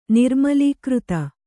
♪ nirmalīkřta